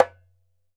ASHIKO 4 0ML.wav